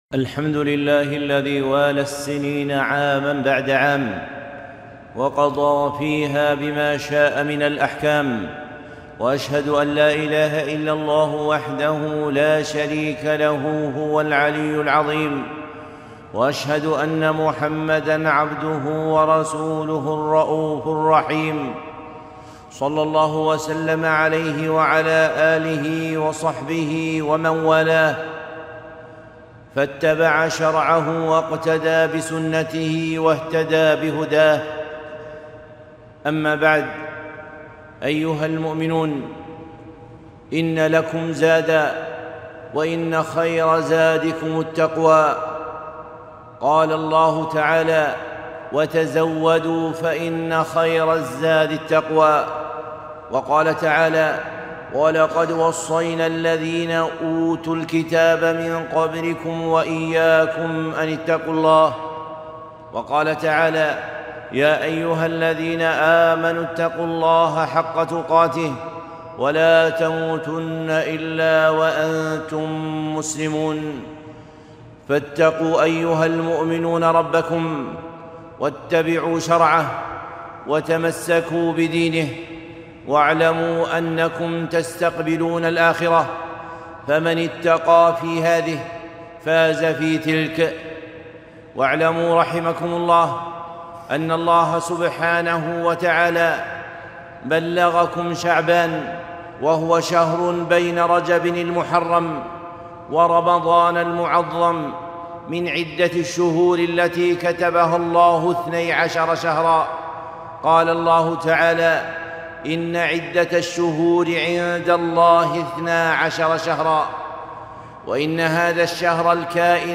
خطبة - طاعة شعبان عون رمضان